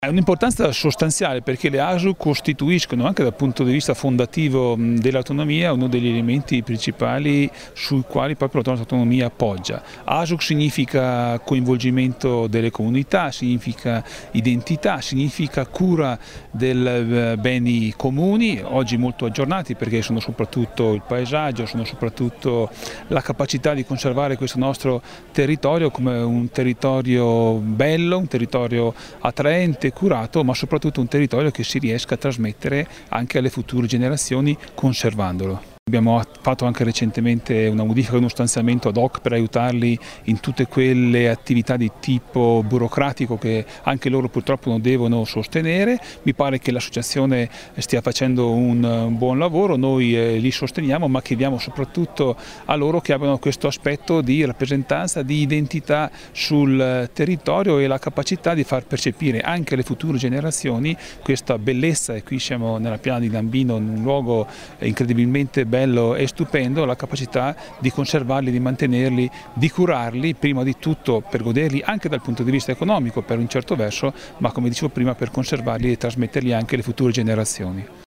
A Madonna di Campiglio la festa